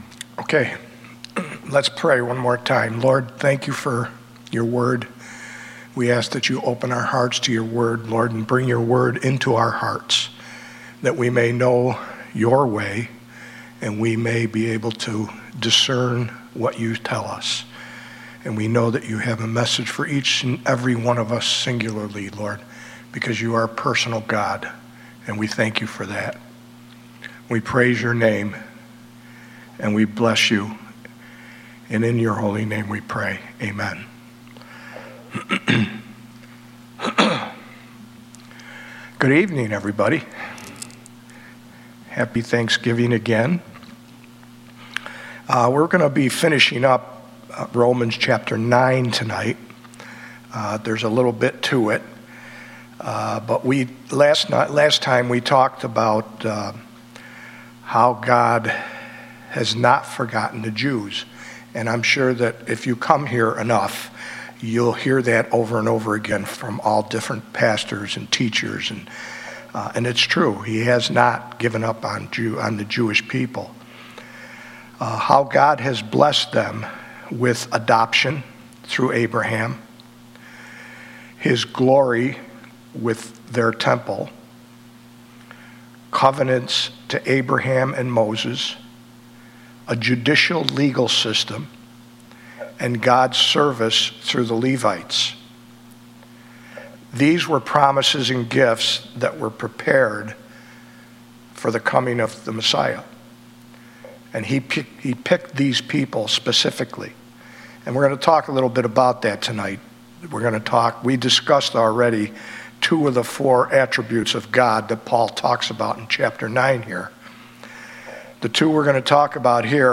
Sunday Night Bible Study